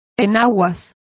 Complete with pronunciation of the translation of petticoat.